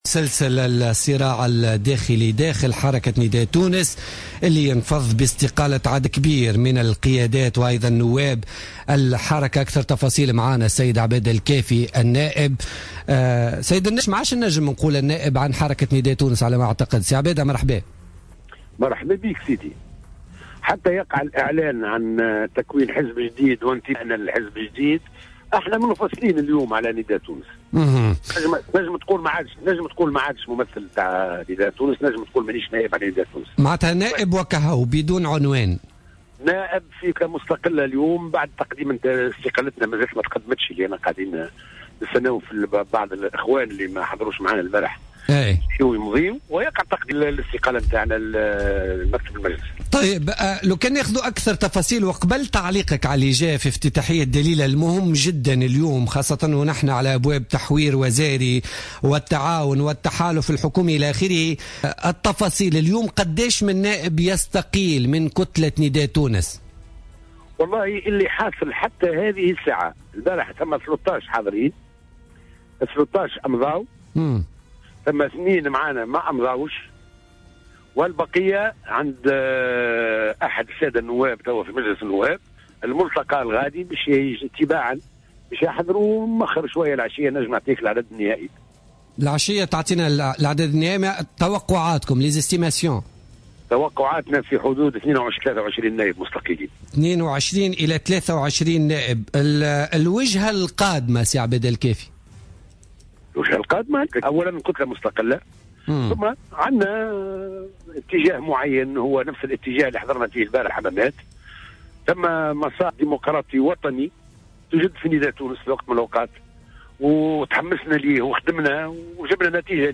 قال القيادي المستقيل من حركة نداء تونس عبادة الكافي وضيف برنامج بوليتكا لليوم الاثنين 21 ديسمبر 2015 إن 23 نائبا من النداء سيتقدمون اليوم رسميا باستقالتهم لرئيس مجلس نواب الشعب محمد الناصر مشيرا إلى إمكانية ولادة حزب جديد.